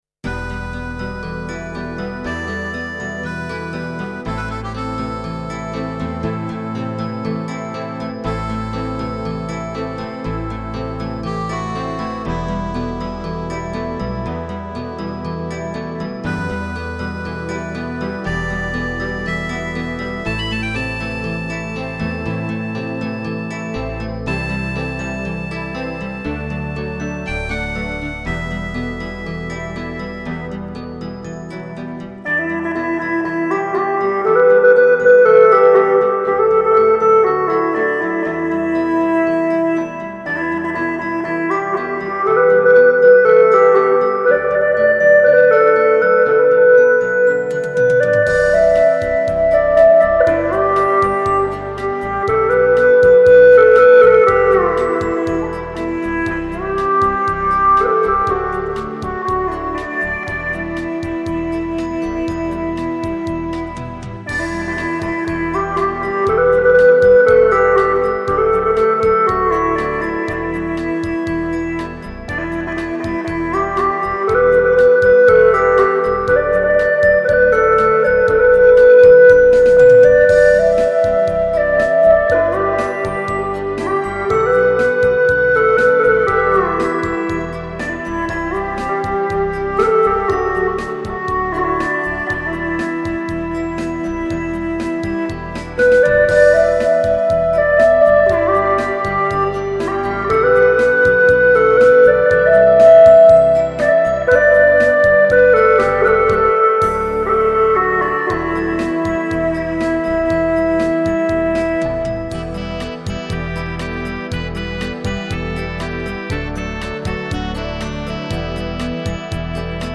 音色优美动听，低音区浑厚；中音区圆润柔美，高音区明亮，在民族乐队中已成为富有表现力的色彩
这两张CD是用巴乌对一些熟悉曲目的重新演绎。